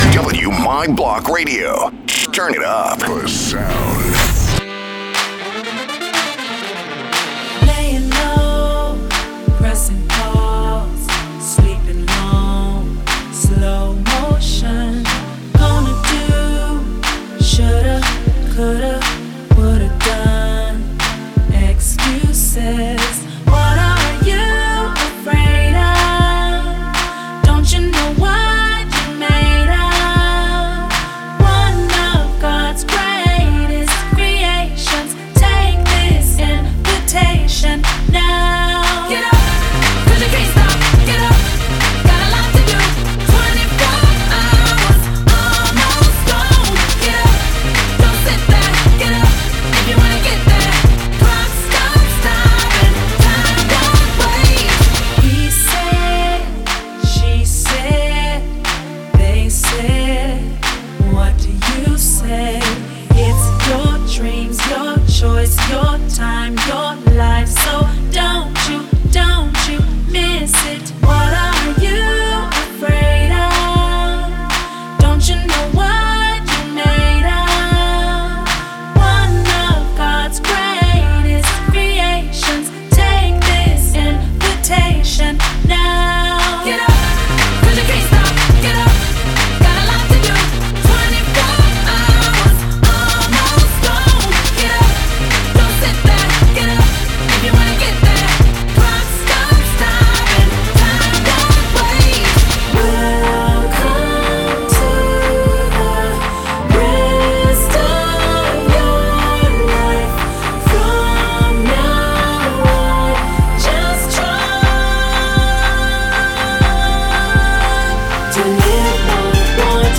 Genre: Christian & Gospel.